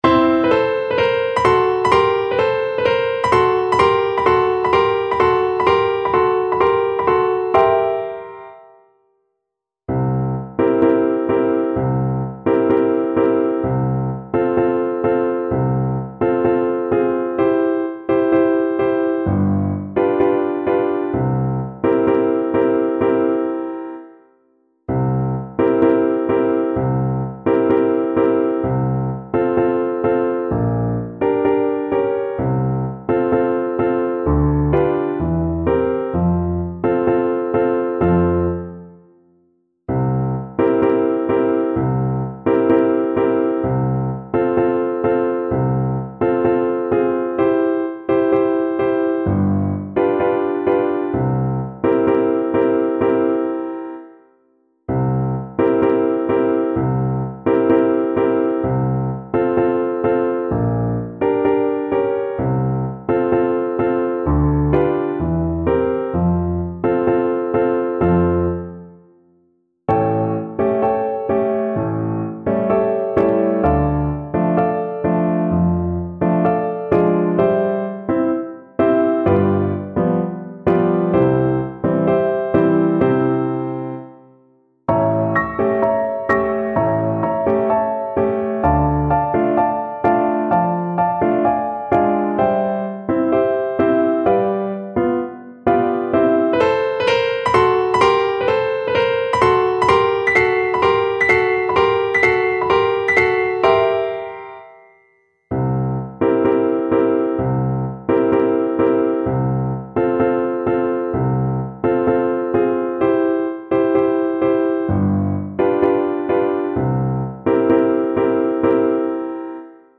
World Chiquinha Gonzaga Atraente (Polka) Flute version
Play (or use space bar on your keyboard) Pause Music Playalong - Piano Accompaniment Playalong Band Accompaniment not yet available transpose reset tempo print settings full screen
Flute
F major (Sounding Pitch) (View more F major Music for Flute )
2/4 (View more 2/4 Music)
Brazilian Choro for Flute